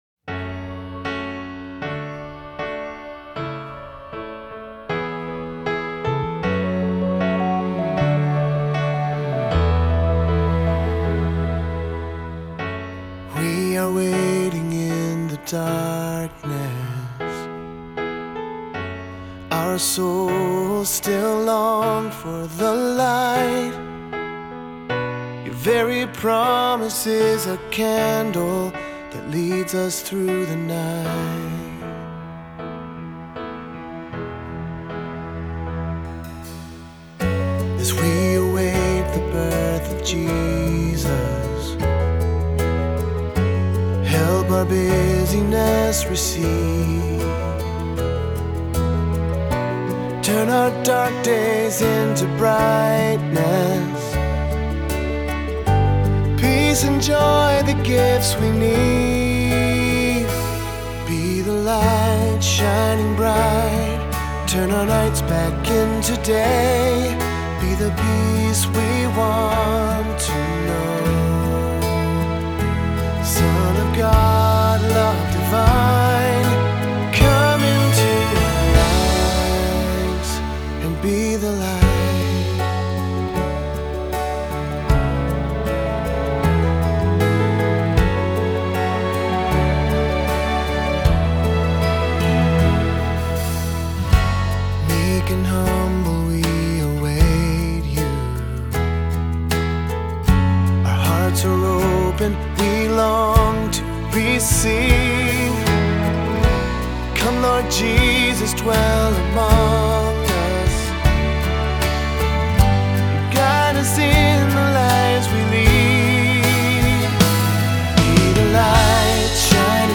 Voicing: Two-part equal; Cantor; Assembly